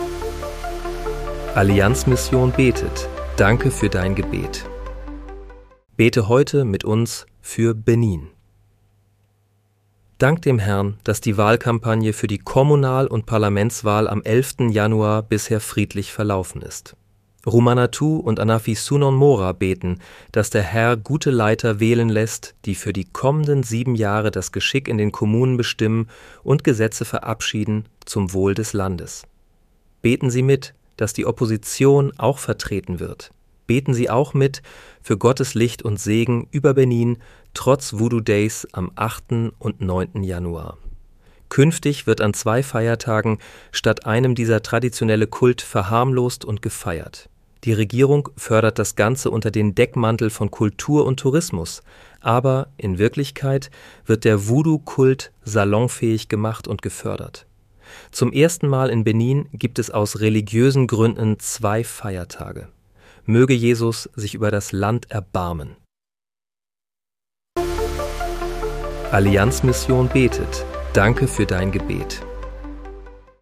Bete am 08. Januar 2026 mit uns für Benin. (KI-generiert mit der